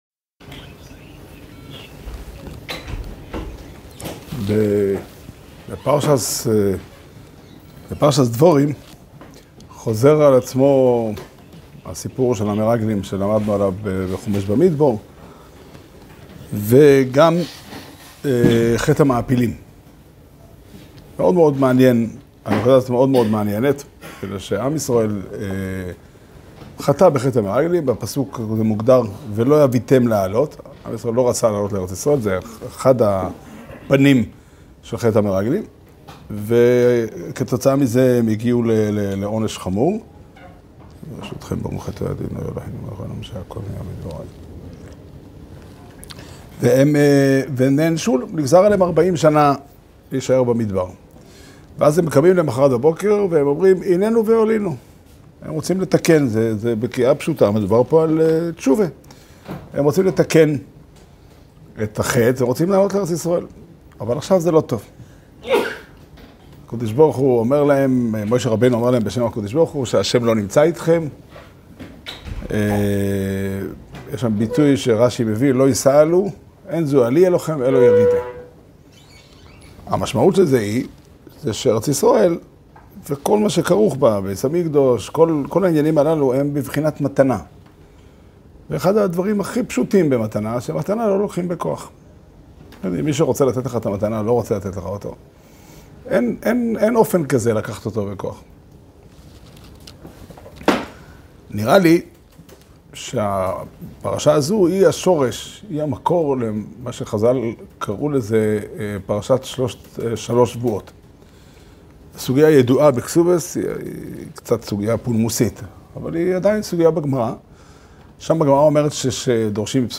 שיעור שנמסר בבית המדרש פתחי עולם בתאריך ד' אב תשפ"ד